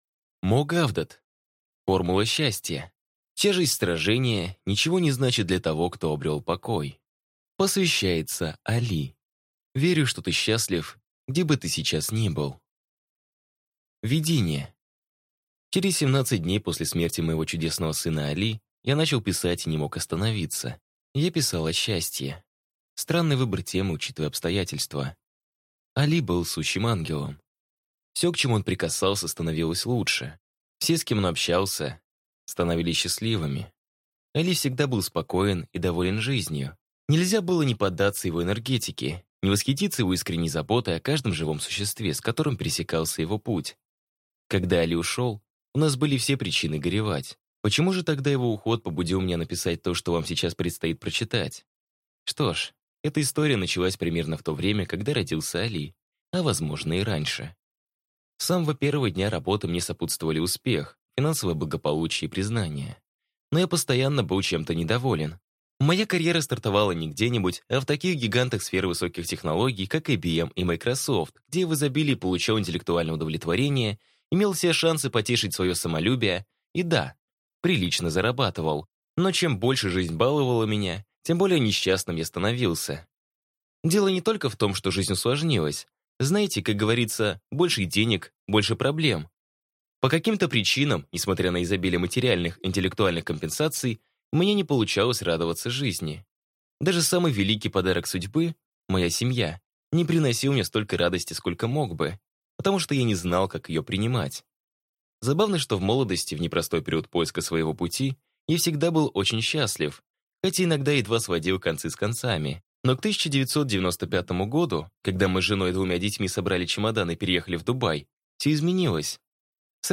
Аудиокнига Формула счастья. Составьте свой алгоритм радости | Библиотека аудиокниг